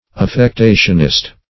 Search Result for " affectationist" : The Collaborative International Dictionary of English v.0.48: Affectationist \Af`fec*ta"tion*ist\, n. One who exhibits affectation.